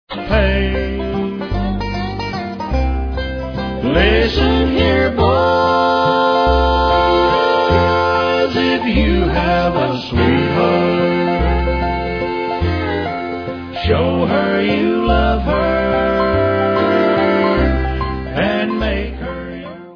sledovat novinky v oddělení Rock/Bluegrass